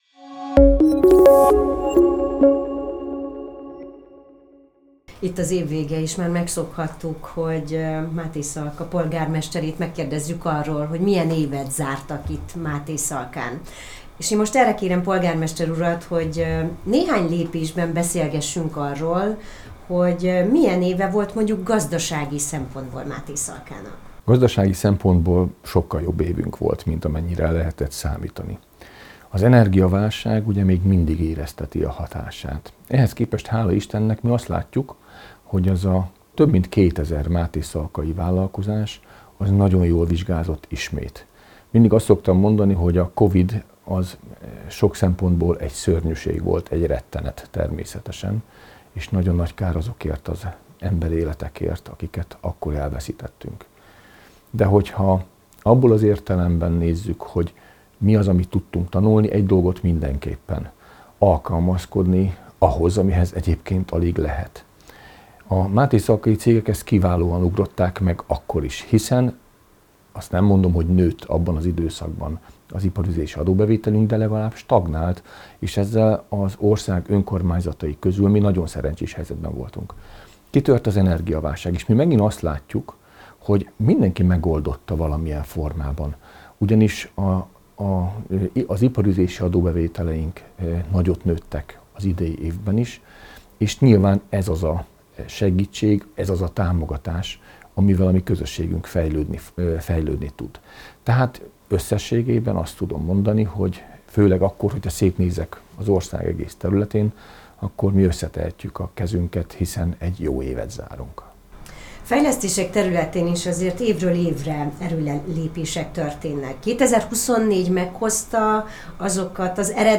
Dr. Hanusi Péter polgármester értékelte az évet.